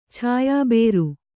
♪ chāyā bēru